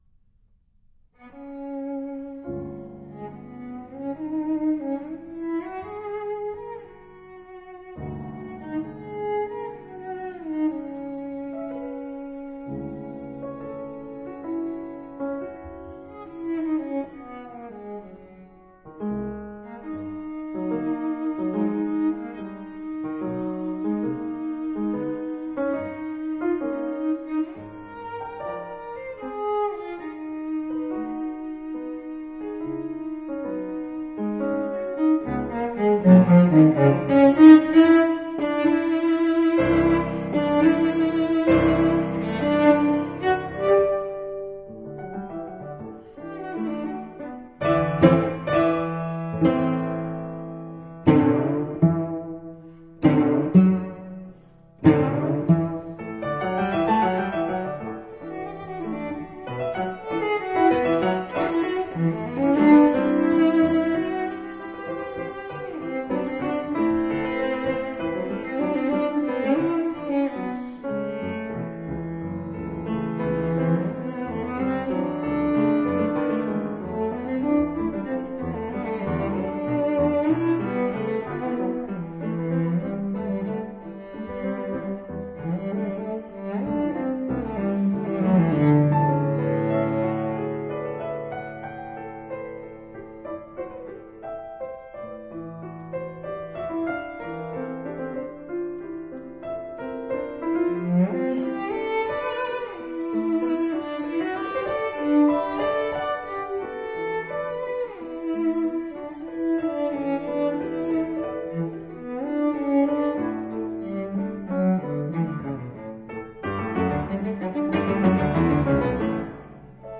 36歲死於車禍，這首作品寫於32歲，瀰漫著與眾不同的氛圍。